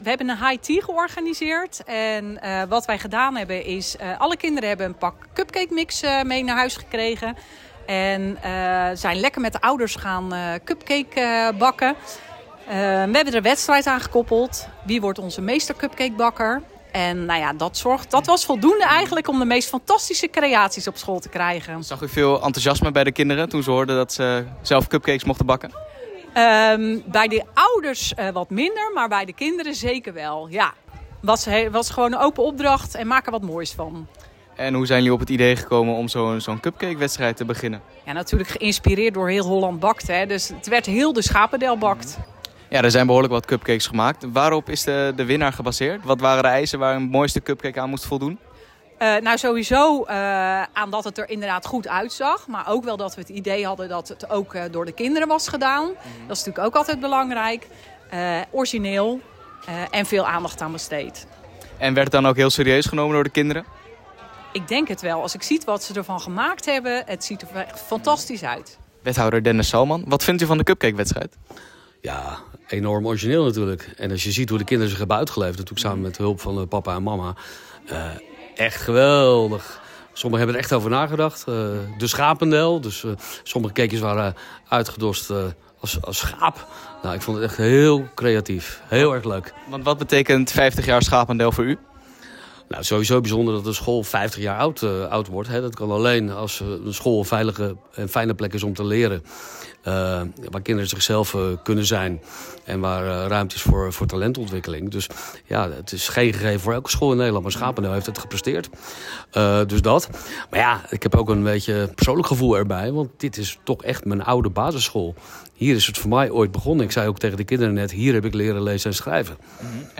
Hieronder de radioreportage